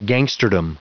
Prononciation du mot gangsterdom en anglais (fichier audio)
Prononciation du mot : gangsterdom